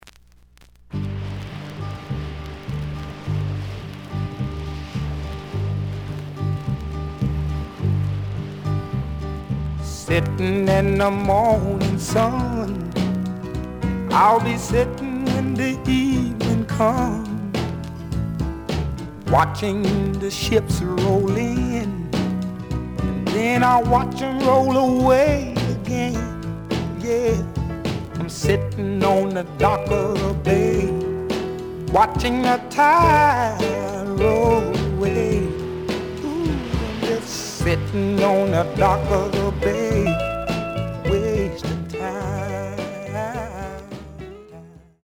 試聴は実際のレコードから録音しています。
The audio sample is recorded from the actual item.
●Genre: Soul, 60's Soul